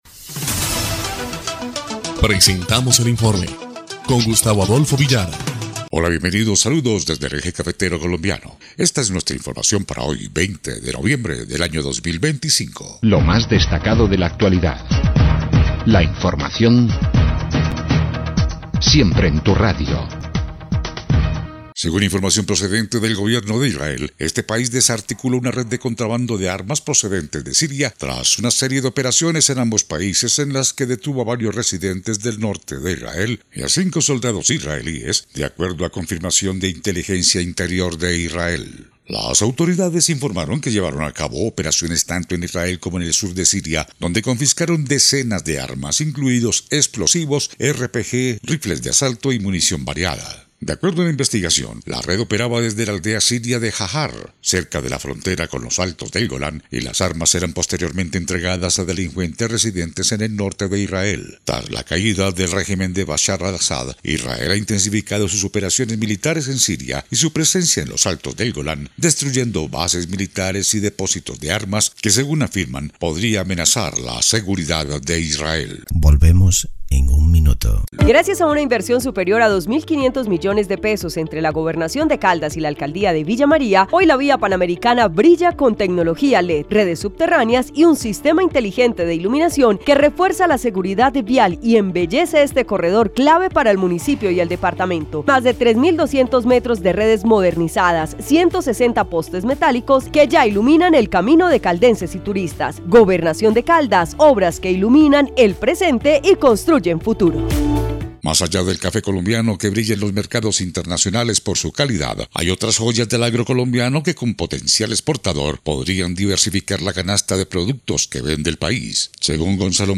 EL INFORME 3° Clip de Noticias del 20 de noviembre de 2025